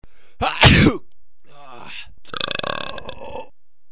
男生打喷嚏打嗝音效免费音频素材下载